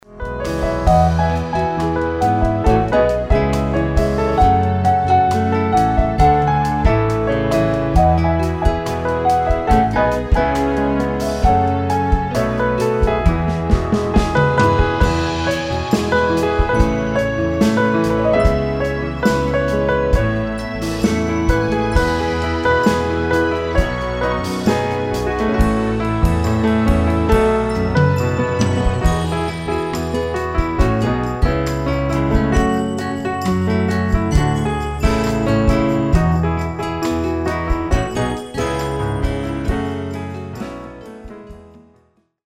Pianist
instrumental recordings